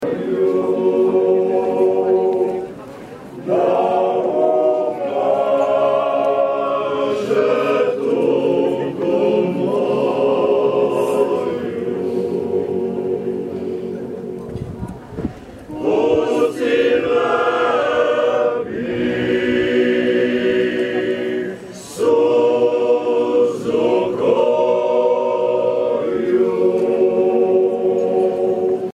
Gerade bei unserer Ankunft intoniert ein Männerchor aus Slowenien ein serbisches Volkslied.
audio_chor.mp3